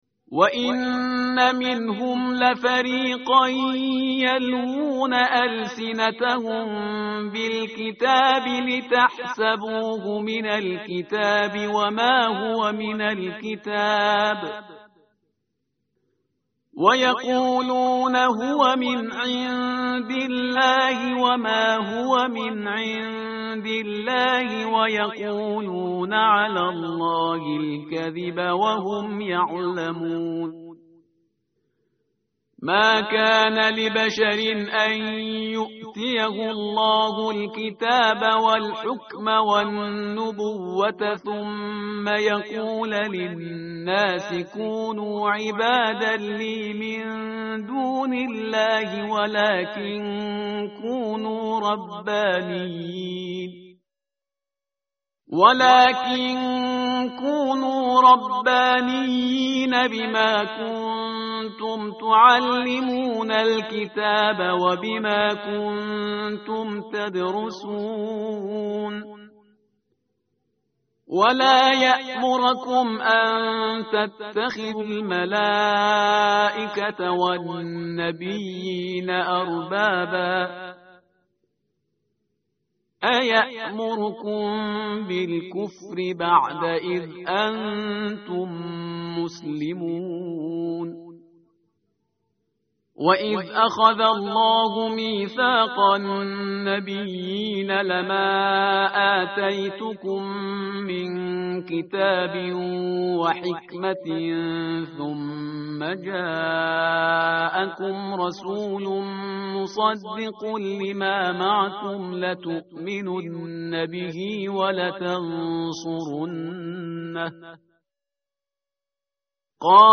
tartil_parhizgar_page_060.mp3